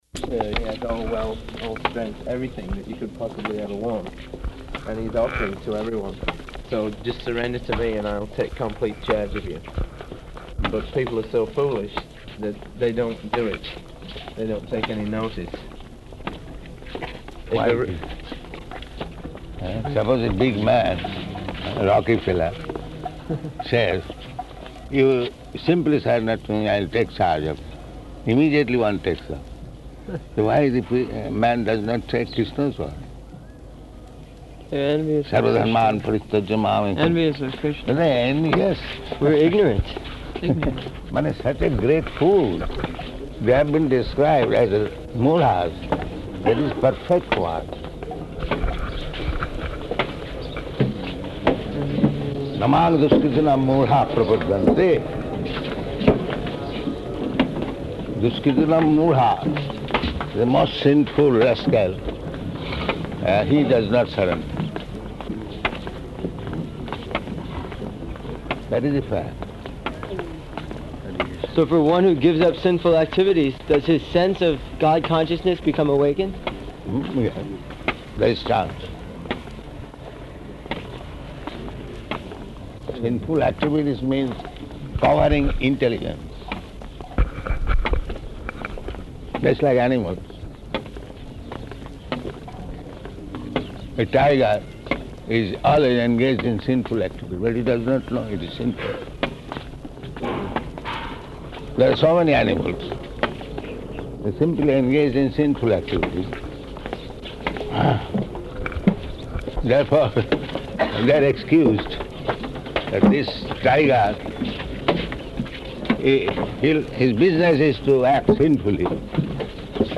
-- Type: Walk Dated: March 14th 1976 Location: Māyāpur Audio file